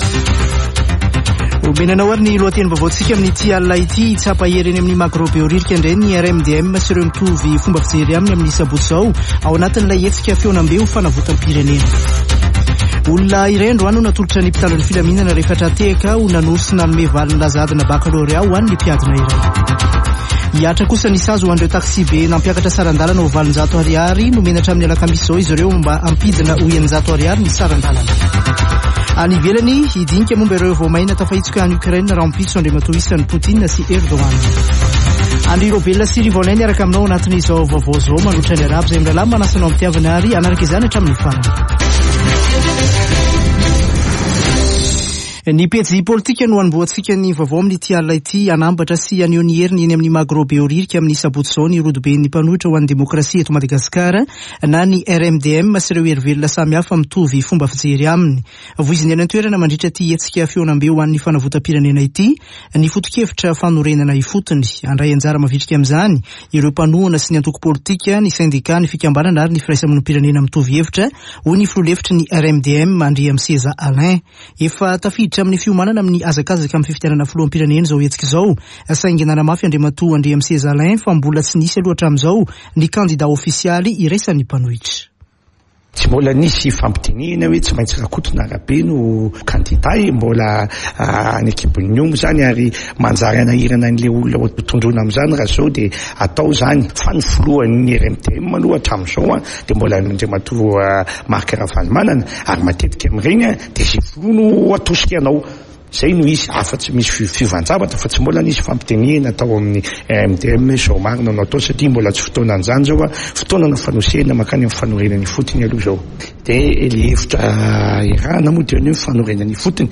[Vaovao hariva] Alatsinainy 18 jolay 2022